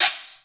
MARACUS DS2.wav